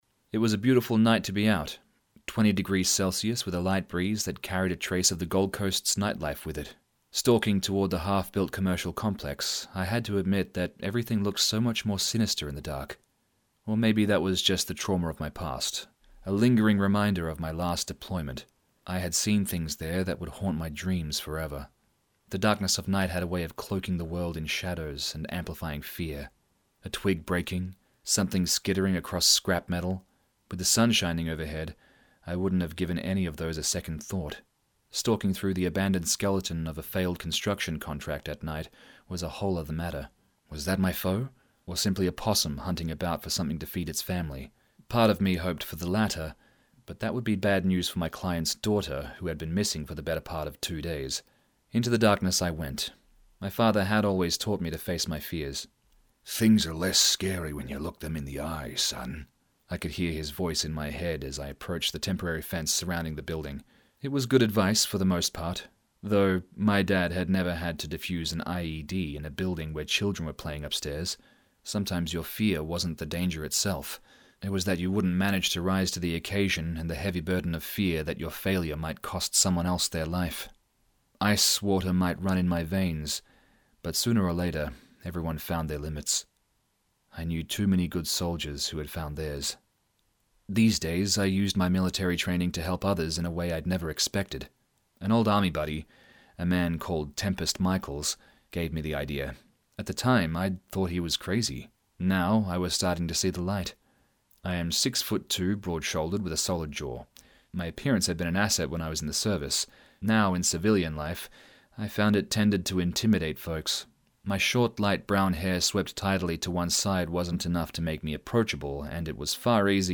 Narrator
Excerpt from Blue Moon Australia Spiteful Spectres by S.C. Stokes and Steve Higgs -